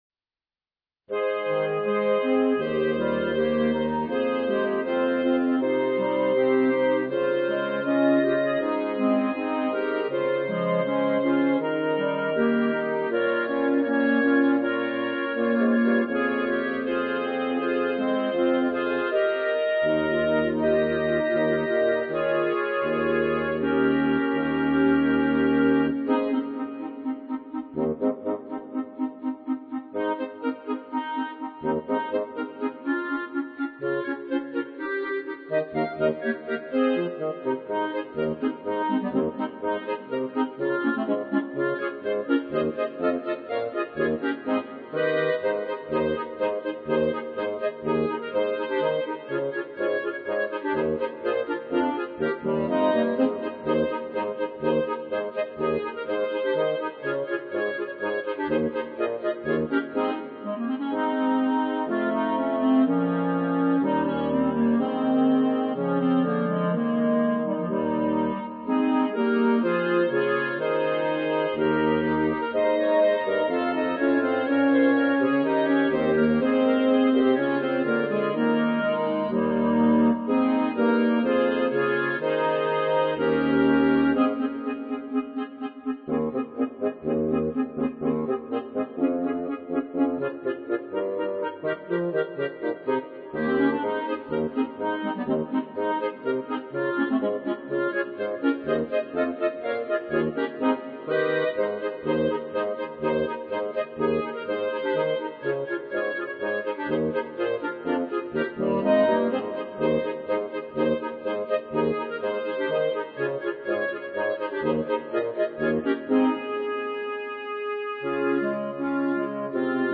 B♭ Clarinet 1 B♭ Clarinet 2 B♭ Clarinet 3 Bass Clarinet
单簧管四重奏
圣诞
八分音符的持续演奏部分请像“叮叮当当”的铃铛声一样演奏。